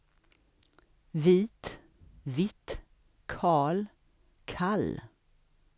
7.7: svensk [vi:t vit: kʰɑ:l kʰal:]